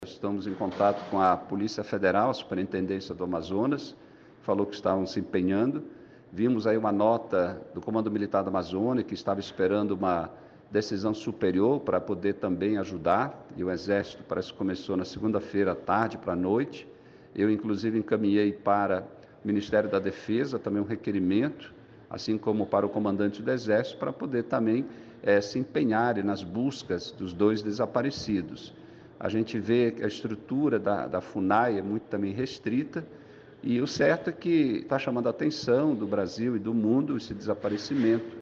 SONORA-02-DEPUTADO.mp3